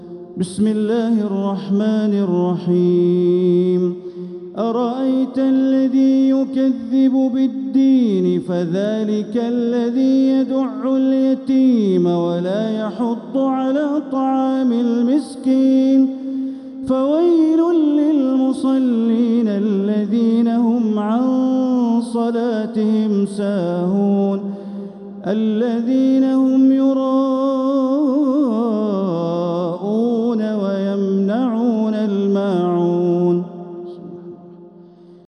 سورة الماعون | مصحف تراويح الحرم المكي عام 1446هـ > مصحف تراويح الحرم المكي عام 1446هـ > المصحف - تلاوات الحرمين